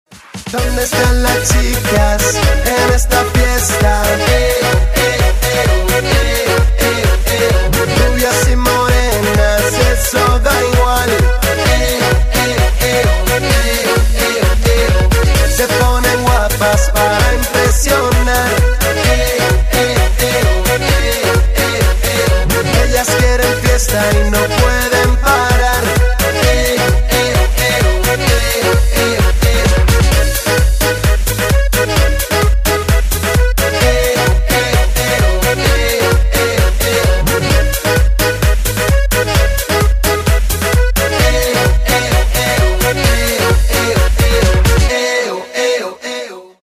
• Качество: 128, Stereo
мужской вокал
громкие
заводные
dancehall
dance
Electronic
EDM
электронная музыка
аккордеон
летние
Reggaeton